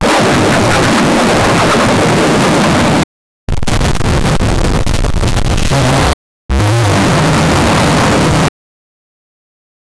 create-a-dj-wicka-wicka-isrr7k7e.wav